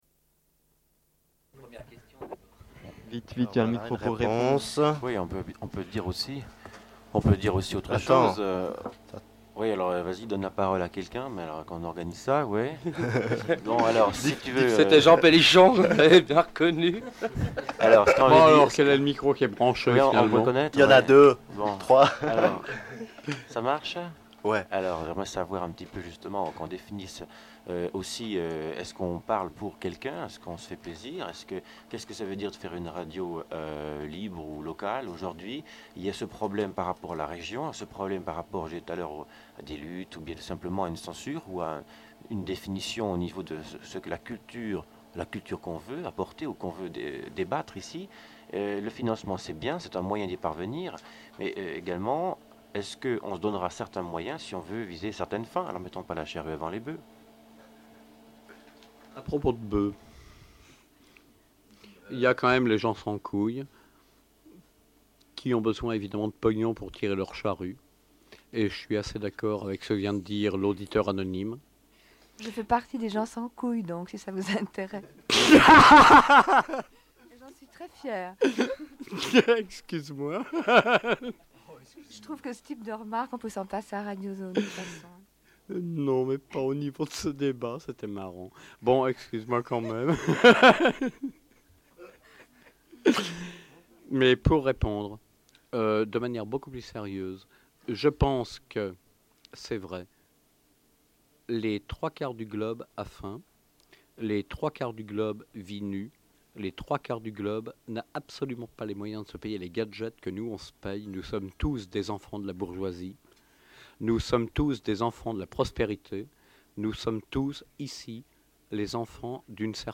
Débat Radio Zones 4/4 - Archives contestataires